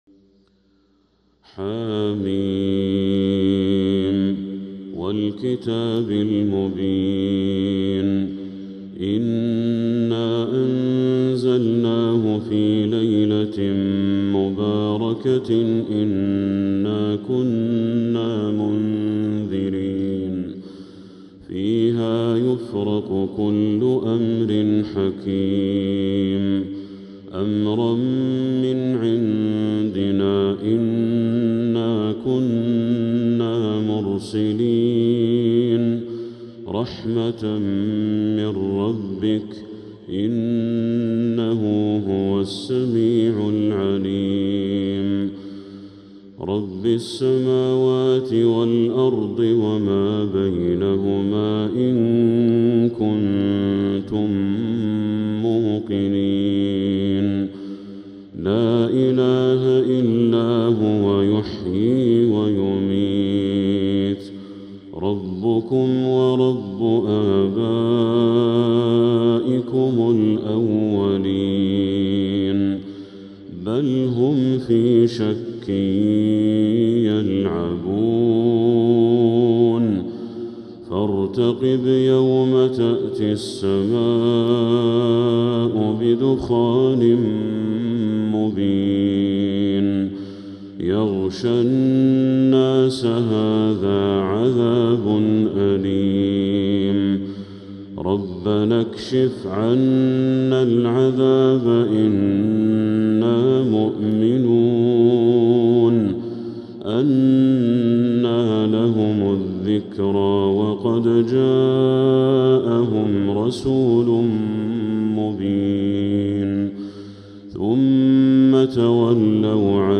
من المسجد الحرام